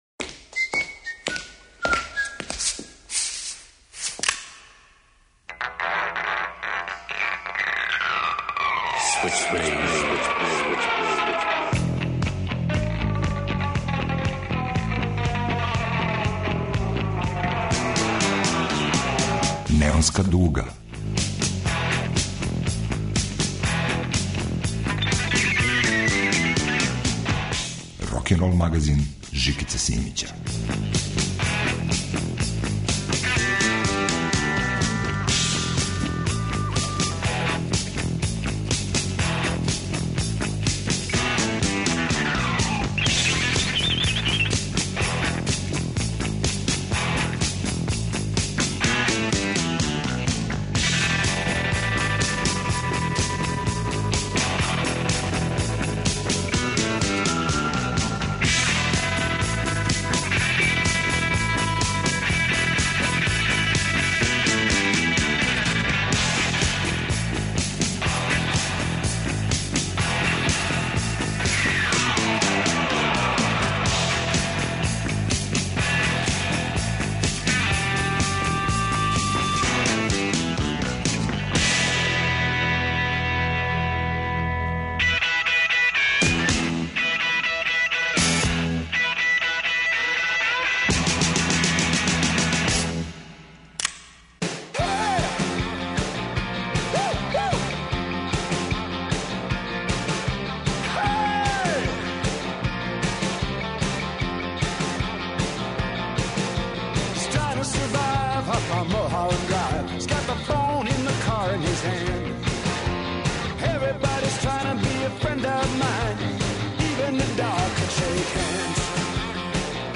Неонска дуга ће испричати причу и одсвирати песме.